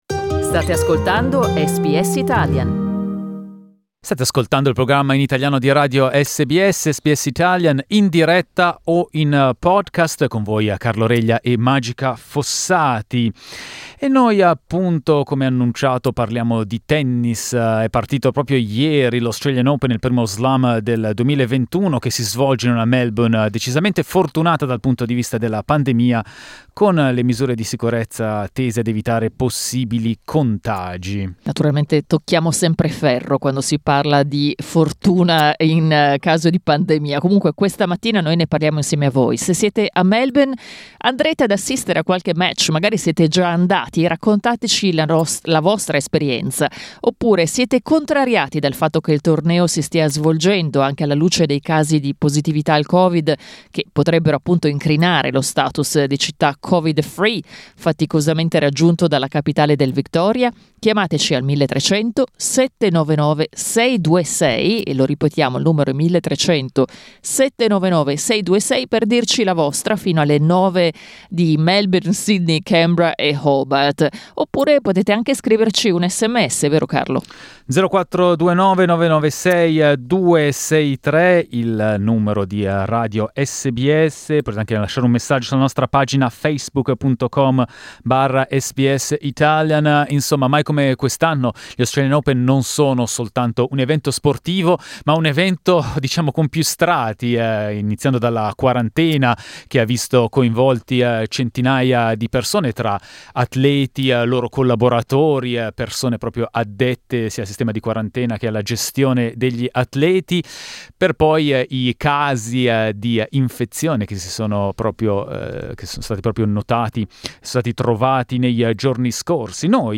Con le linee aperte a SBS Italian , i nostri ascoltatori ci hanno raccontato le loro impressioni e le loro emozioni.